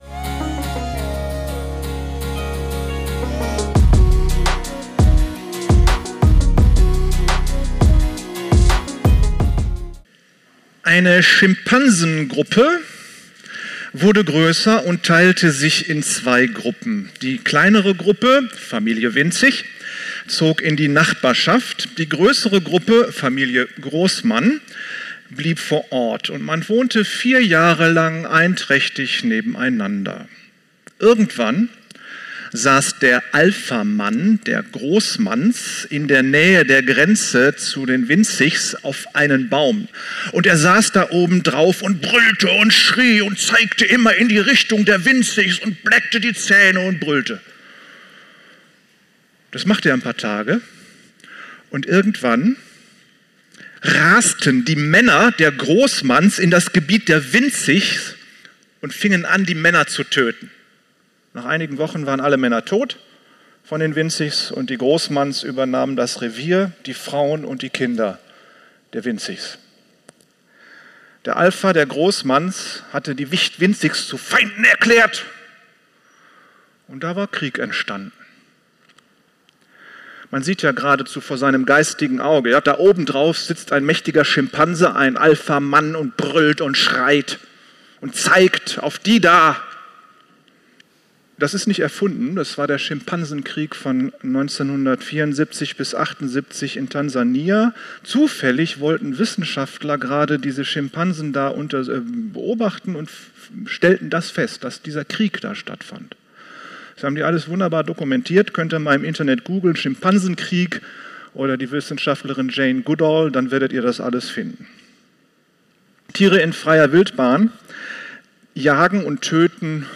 Die Predigt ist in Zusammenarbeit mit der Gemeindeleitung entstanden.